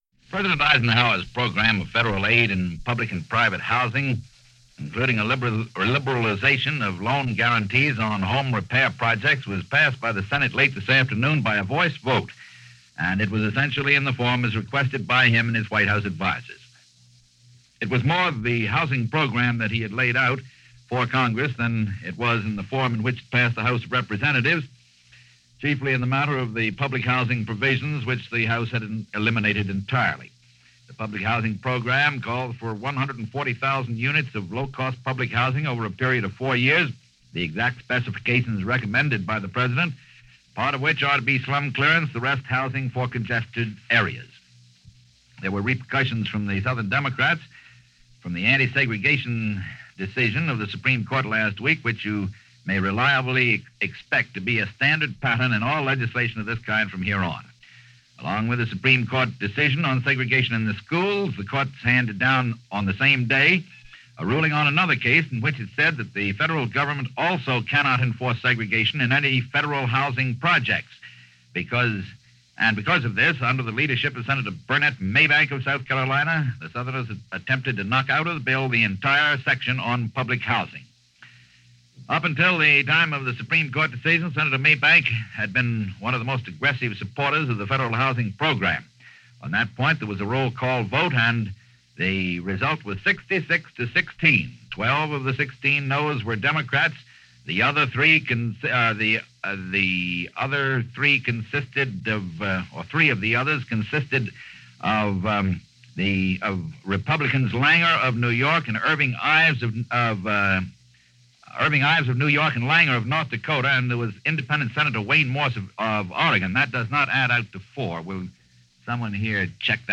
June 3, 1954 - The Politics Of Civil Rights - Segregation, Capitol Hill and Communists - The Eisenhower Years - News for this day in 1954.